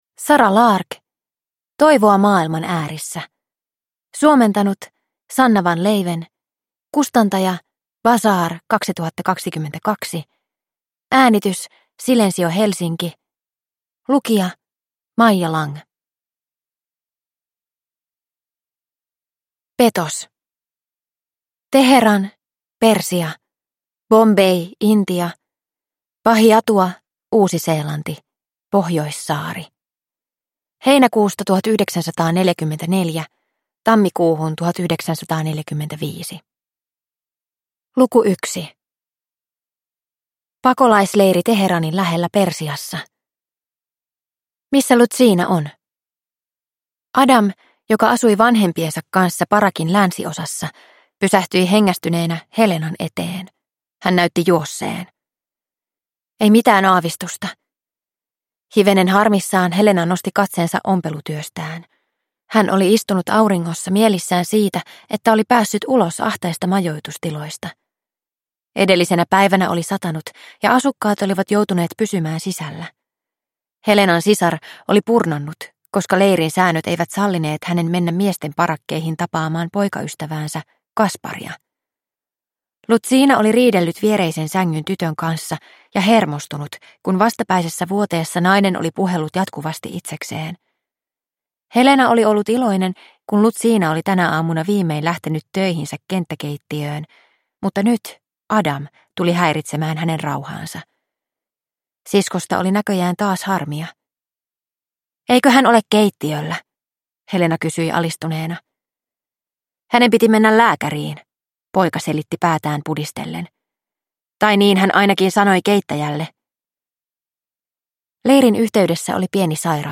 Toivoa maailman äärissä – Ljudbok – Laddas ner